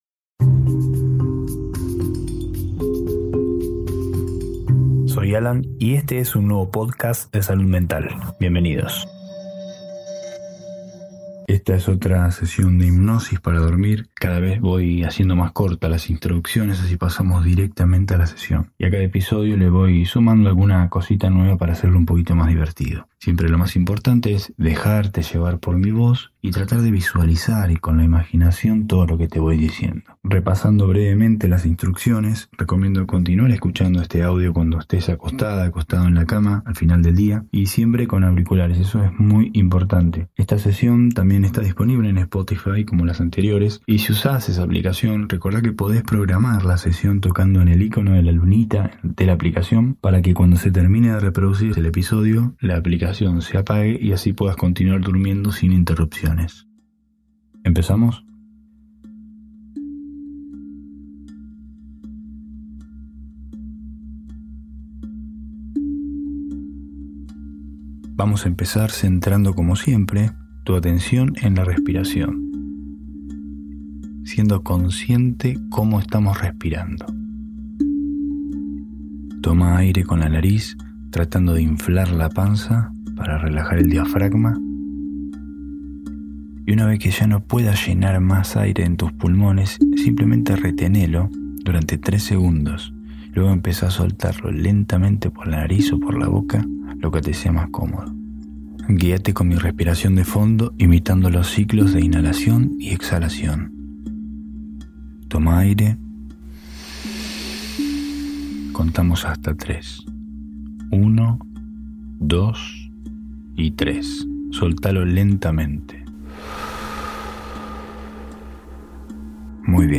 Sesión de autohipnosis guiada para enfrentar el insomnio, inducir el sueño profundo y dormir mejor.